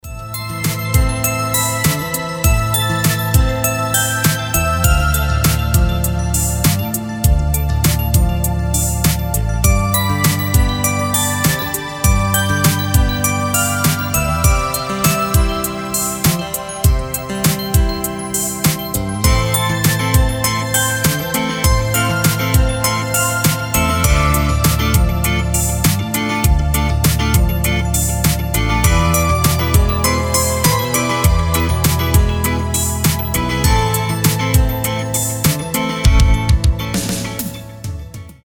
без слов
Инструментальные , Романтические рингтоны